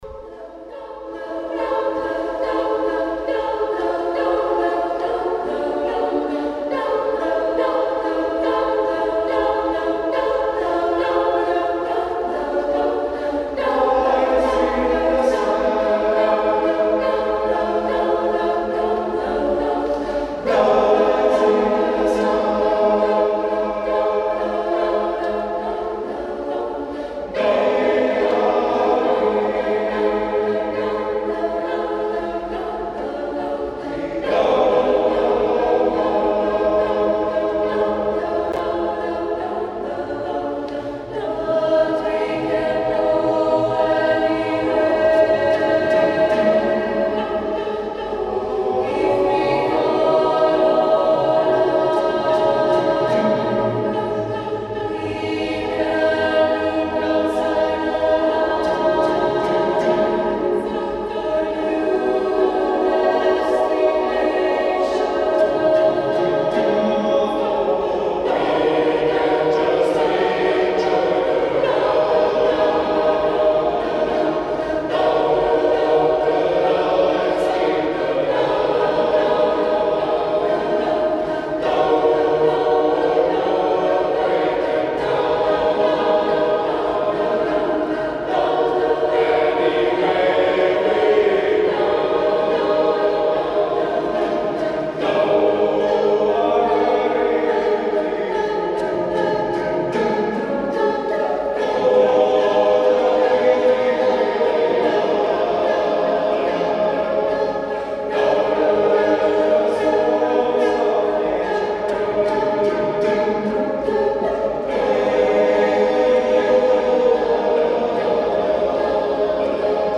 Beszélgetőtárs